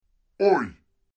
Звуки ой
пониженный тон